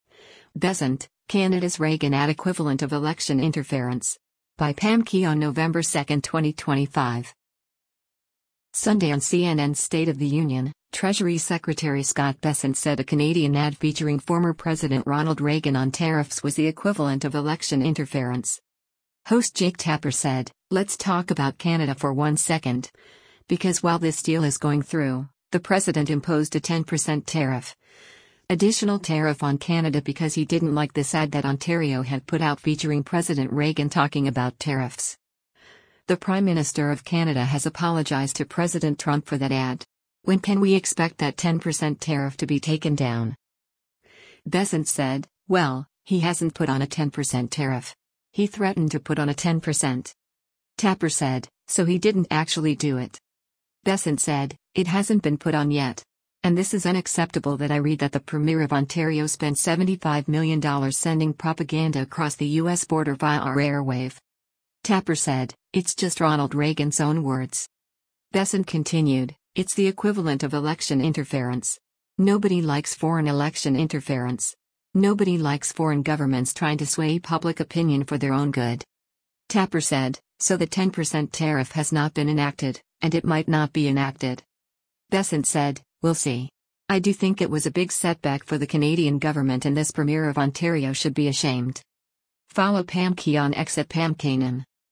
Sunday on CNN’s “State of the Union,” Treasury Secretary Scott Bessent said a Canadian ad featuring former President Ronald Reagan on tariffs was “the equivalent of election interference.”